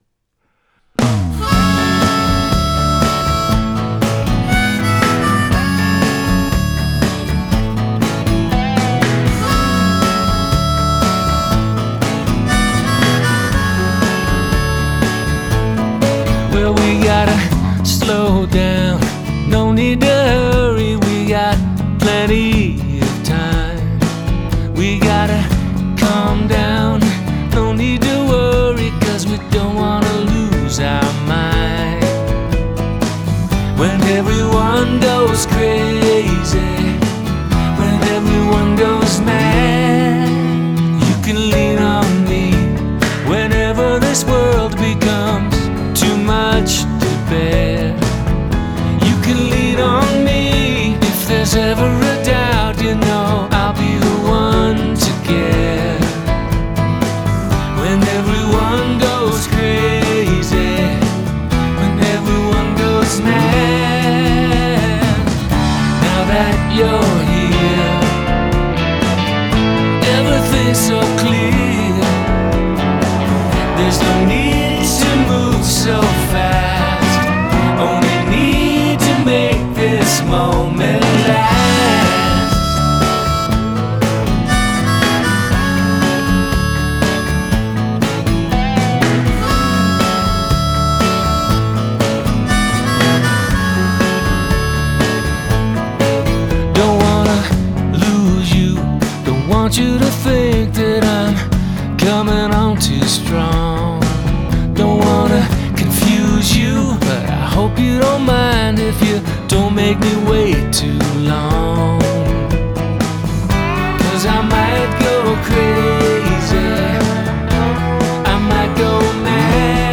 lightly swinging poprock gem